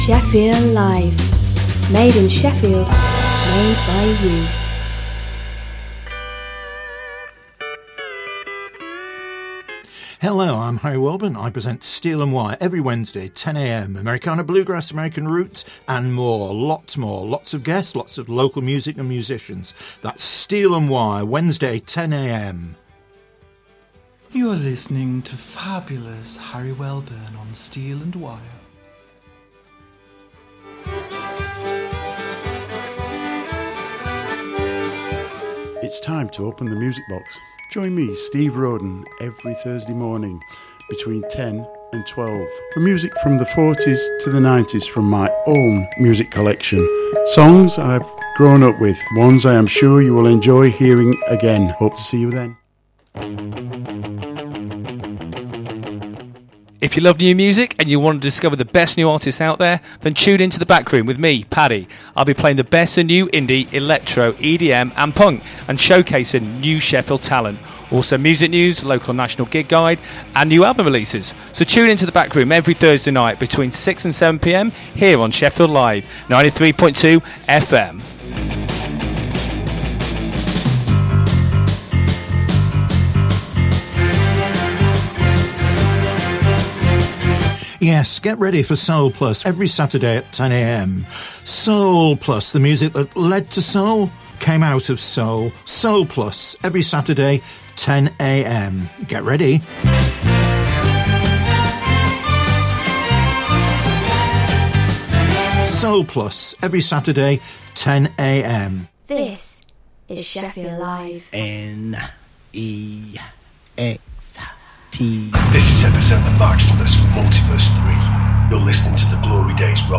2 hours of the best popular classic and Prog rock music plus Gig and band info..